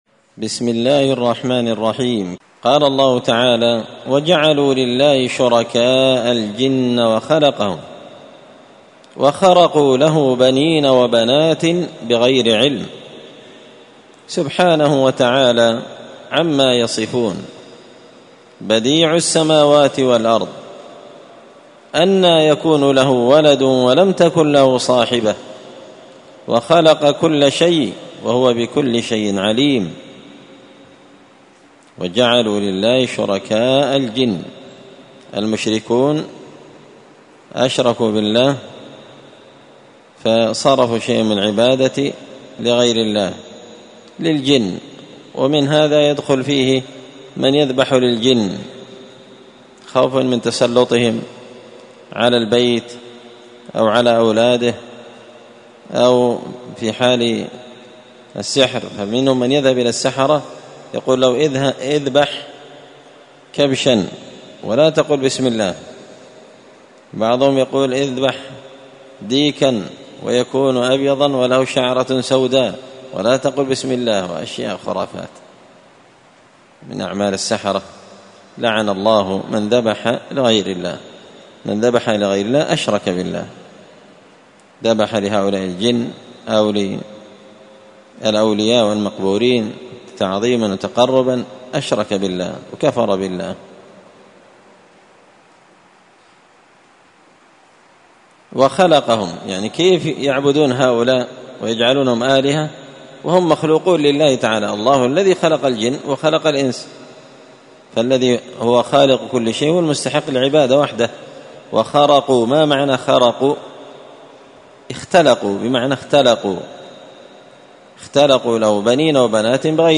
مسجد الفرقان قشن_المهرة_اليمن
مختصر تفسير الإمام البغوي رحمه الله الدرس 336